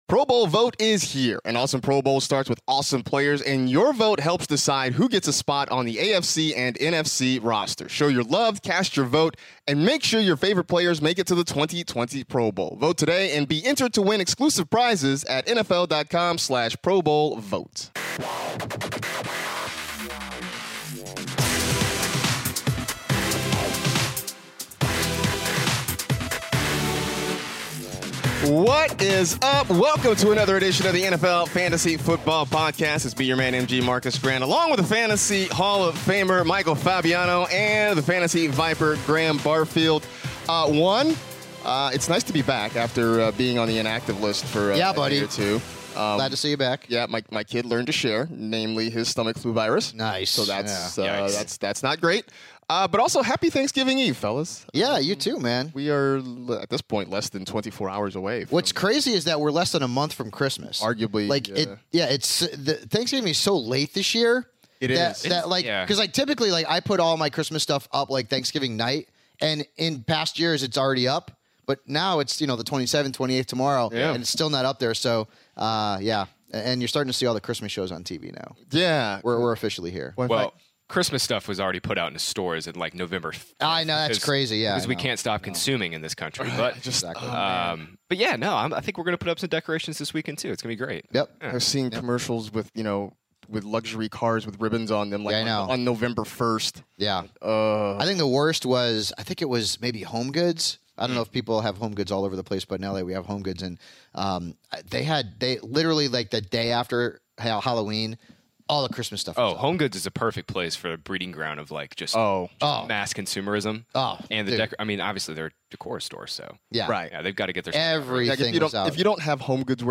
back in the studio